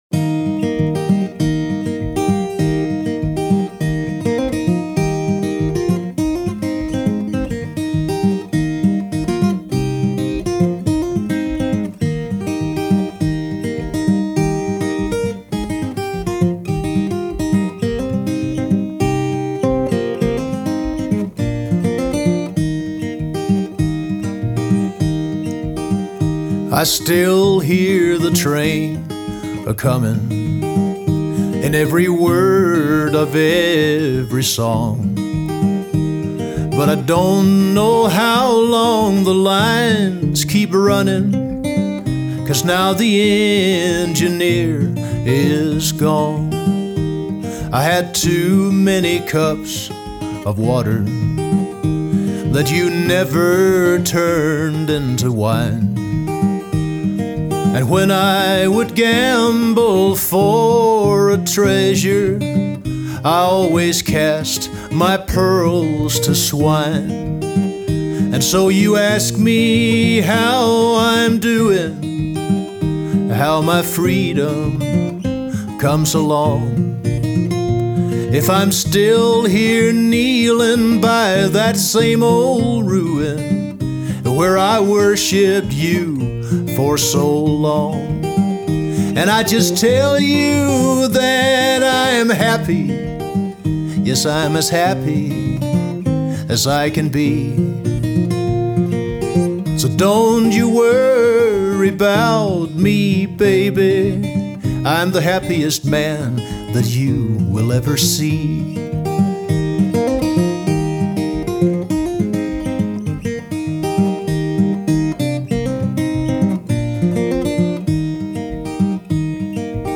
with his deep baritone and barebones accompaniment.